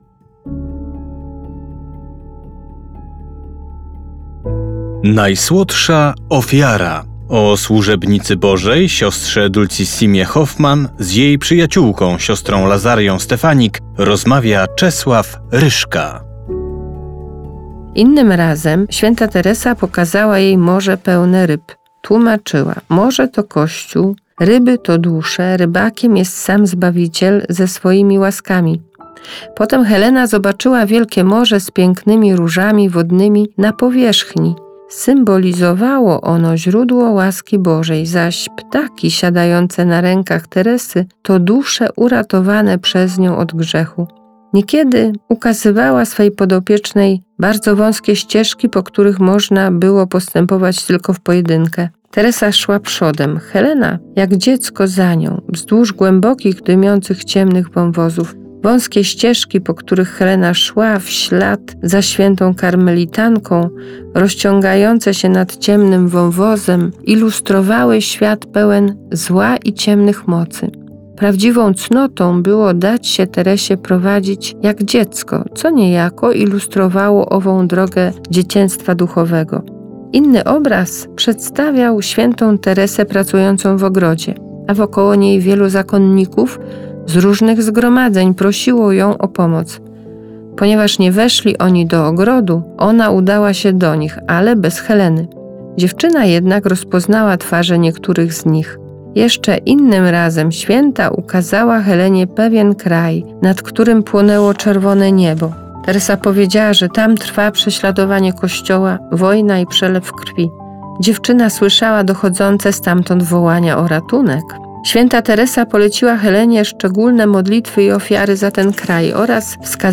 Propozycją duszpasterską Radia Rodzina na Wielki Post jest specjalny audiobook pt. „Dulcissima -Najsłodsza Ofiara”.